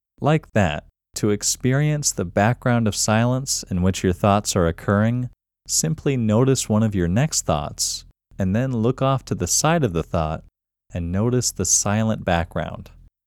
QUIETNESS Male English 8
The-Quietness-Technique-Male-English-8.mp3